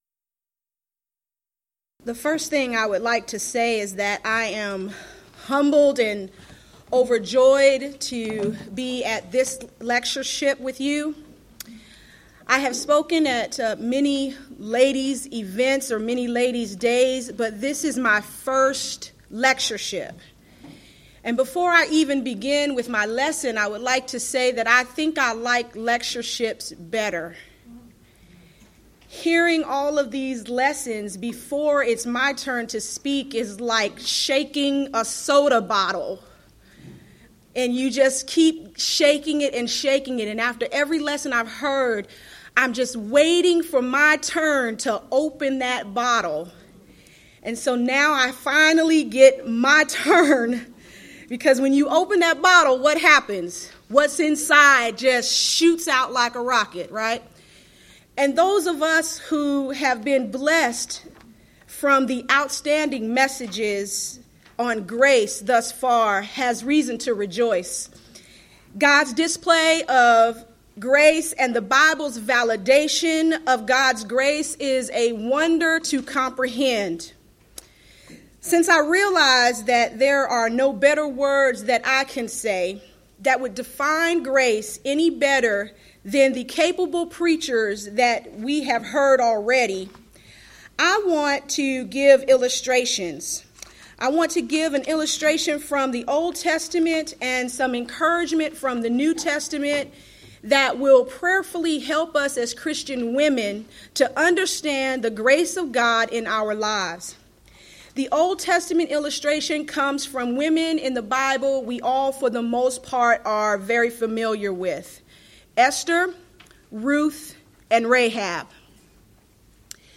Event: 21st Annual Gulf Coast Lectures
lecture